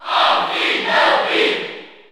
Category: Crowd cheers (SSBU) You cannot overwrite this file.
Greninja_Cheer_French_NTSC_SSBU.ogg